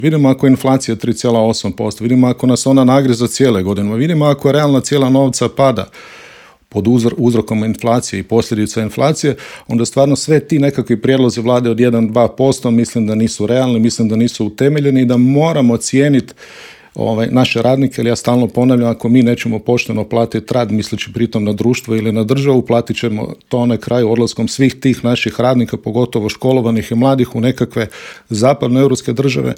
Gostujući u Intervjuu Media servisa